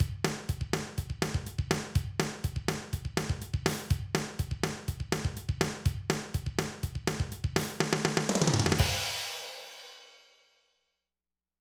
Boss Dr Rhythm DR-3 Sample Pack_Loop12.wav